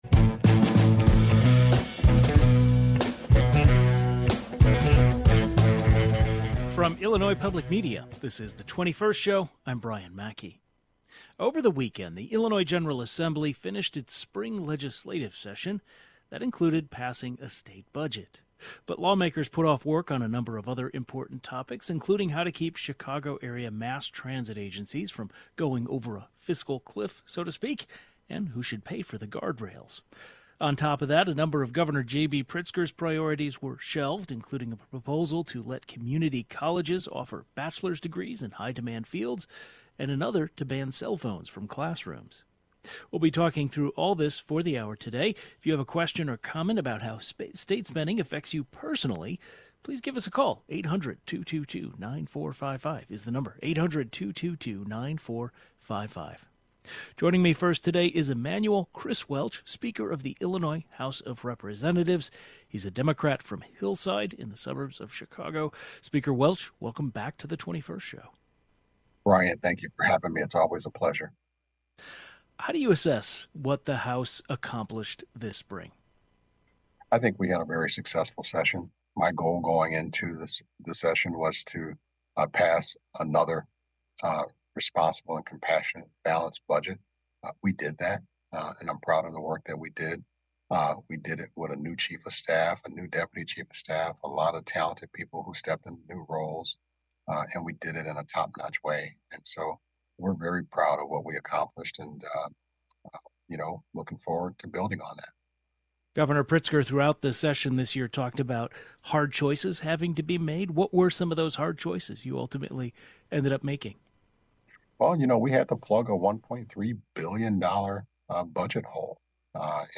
Chris Welch, Speaker of the Illinois House of Representatives shares his thoughts on the budget.